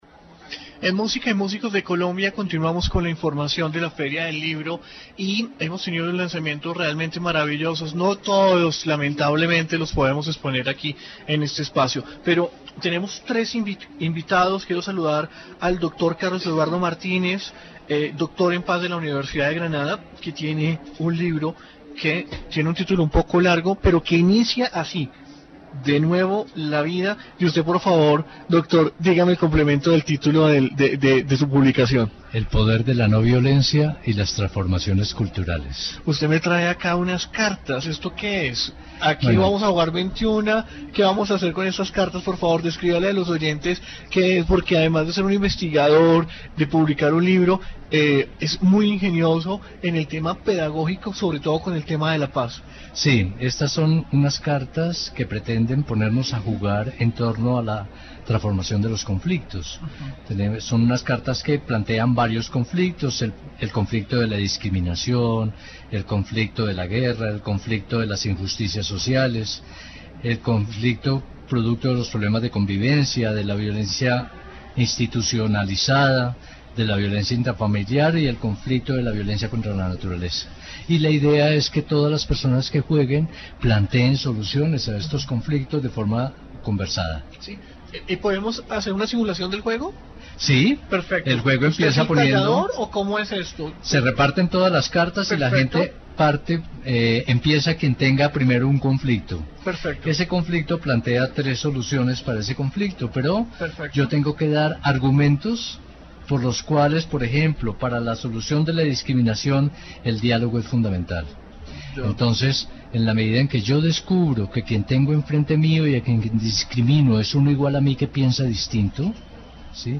Feria del Libro 2017. Informe radial